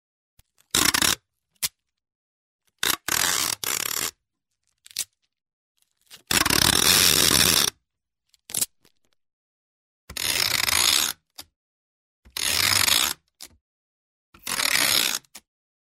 Szalagtartó, szalag karton felett: